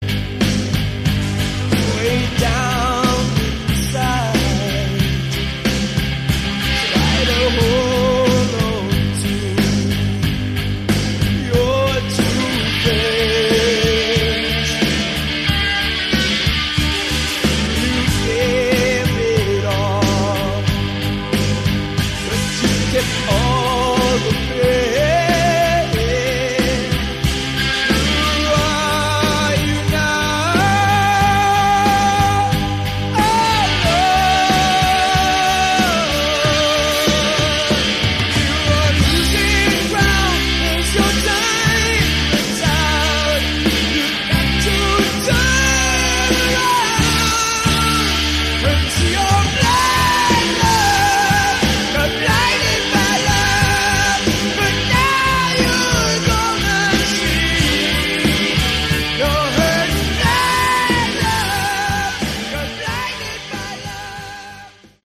Category: Hard Rock
lead and backing vocals
keyboards
guitar
drums